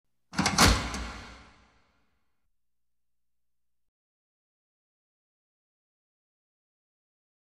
Big Metal Freezer Door Open With Latch Clicks And Reverb